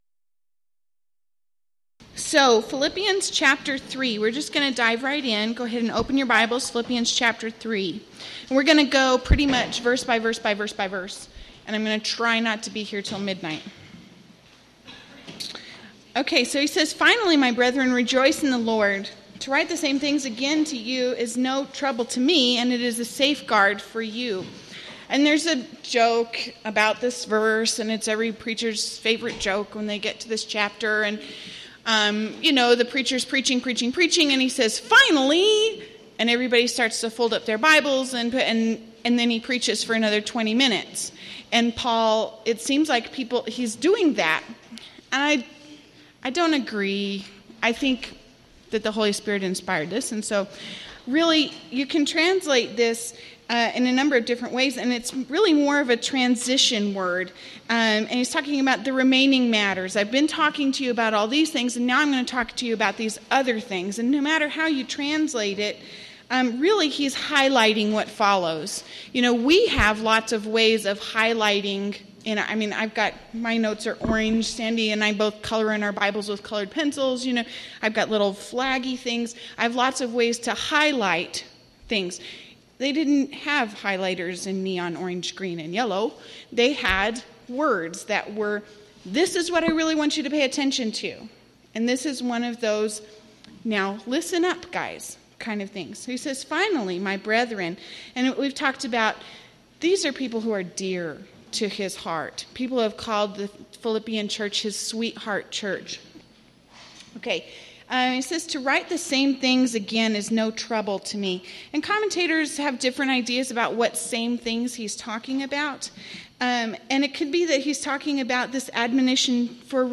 Event: 1st Annual TLC Retreat
Ladies Sessions